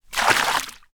Water_57.wav